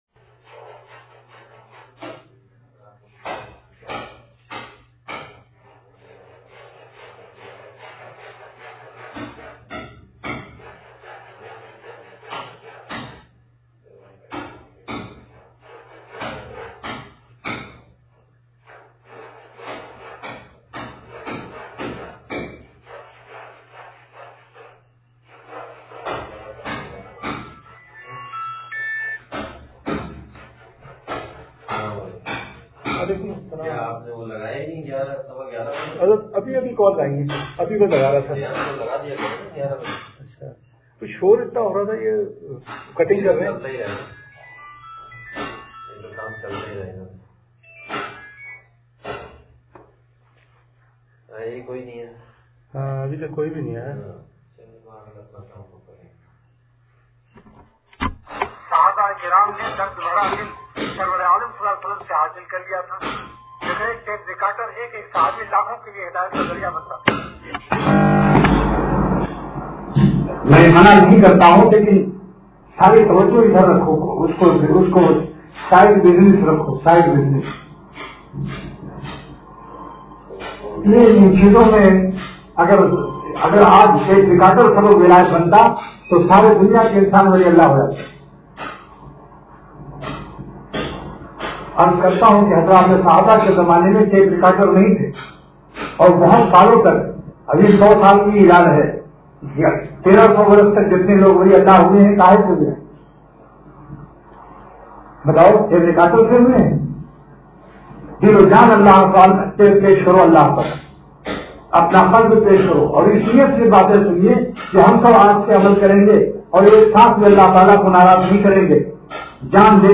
بیان – حضرت والا رحمت اللہ علیہ – اتوار